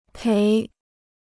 赔 (péi): to compensate for loss; to indemnify.